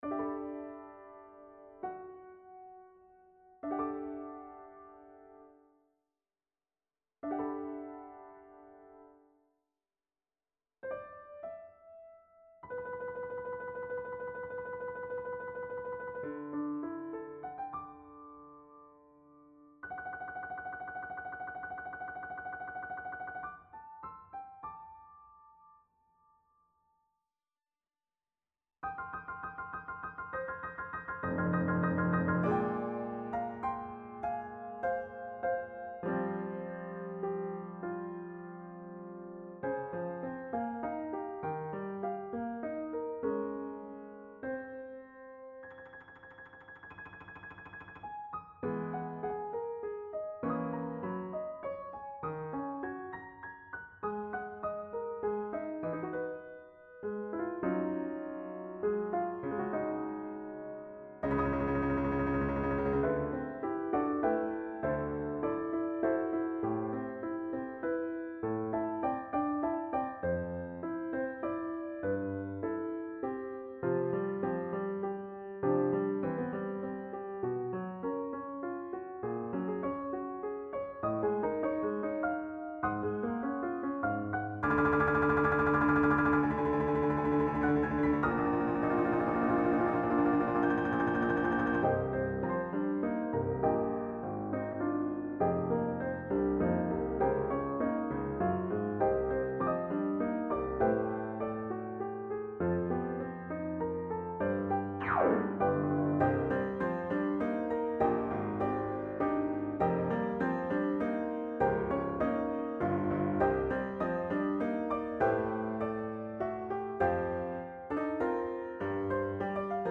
accompaniment Mp3